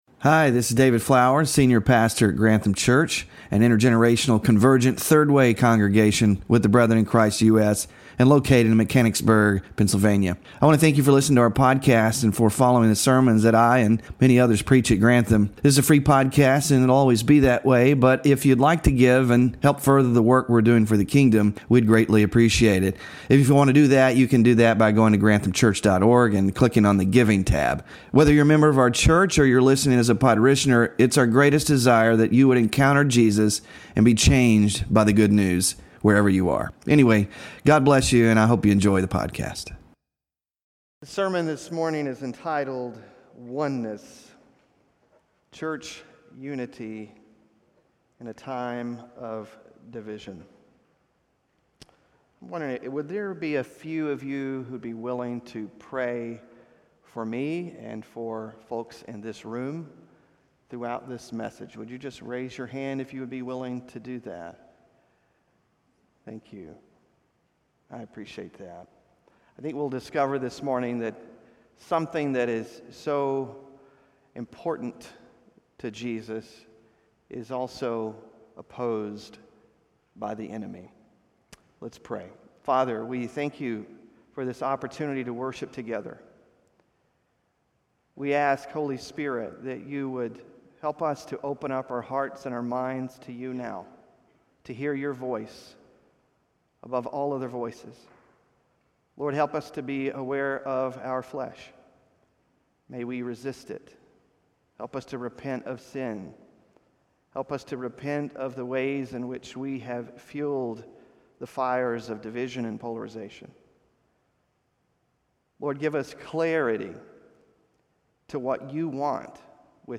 WORSHIP RESOURCES Sermon Slides (4-14-24) Small Group Discussion Questions